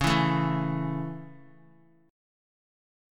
Dbm#5 chord